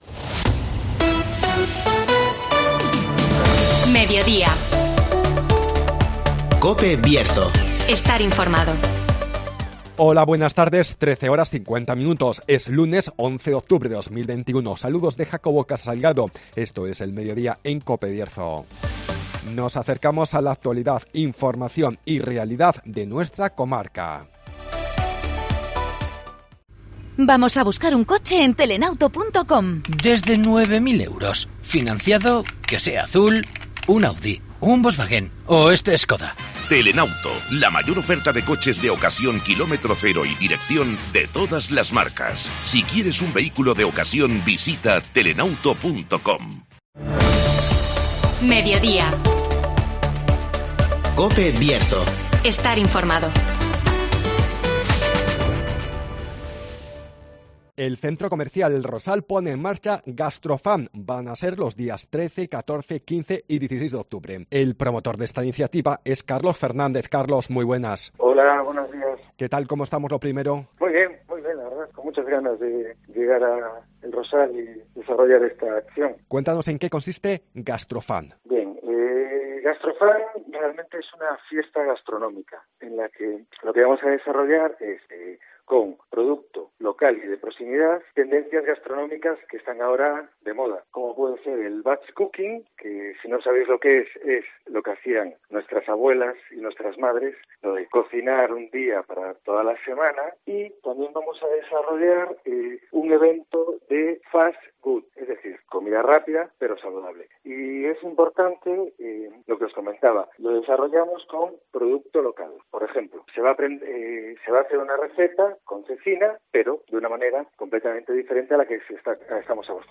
El Rosal pone en marcha GastroFan (Entrevista